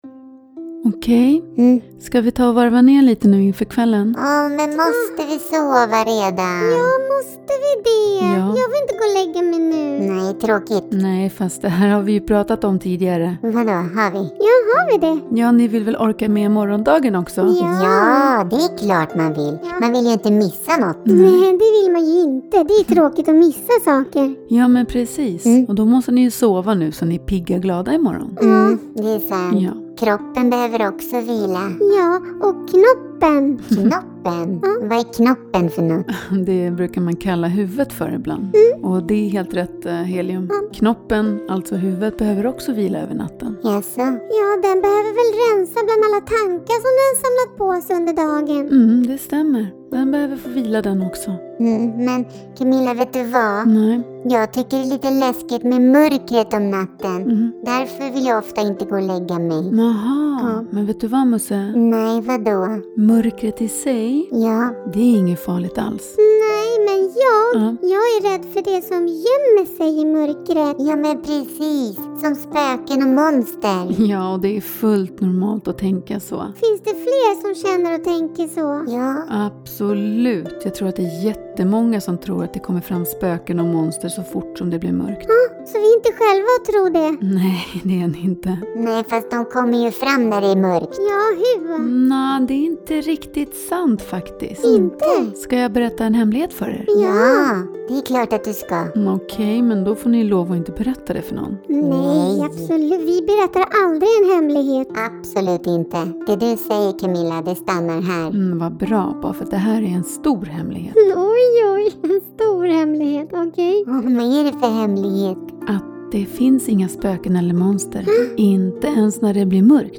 En godnattstund med inslag av mindfulness. Avkopplande, lugnande, sövande och perfekt innan barnen ska sova.